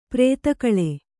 ♪ prēta kaḷe